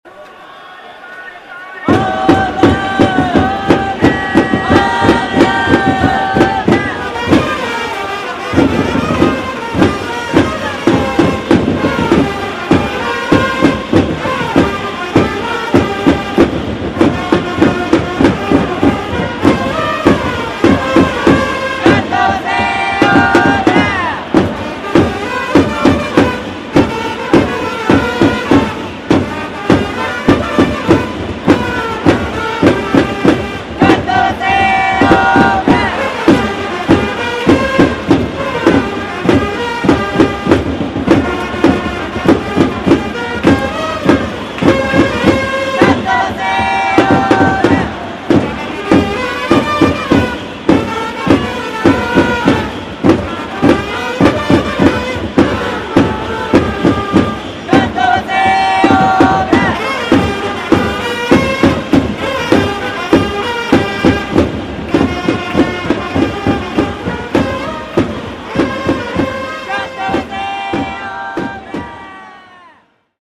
近鉄曲は、近鉄特有のド短調な曲。
一方ホークス曲は、うって変わって爽やかな曲調。